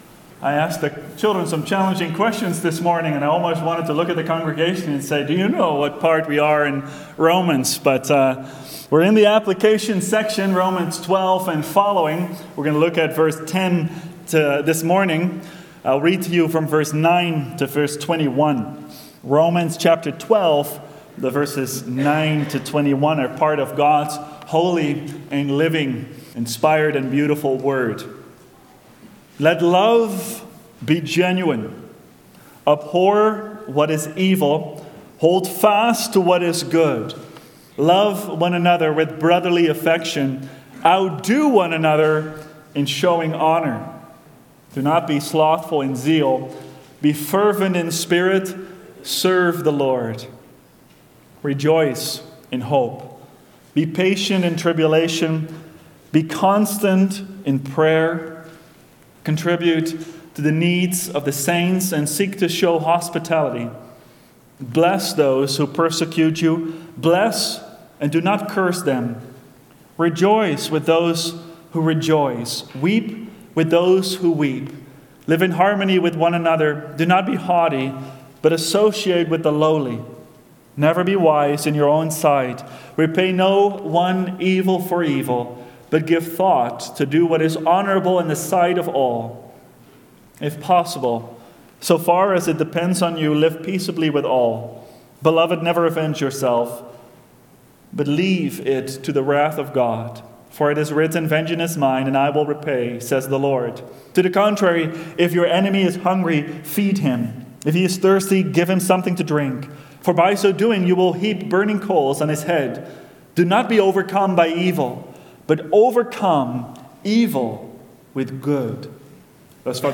preaches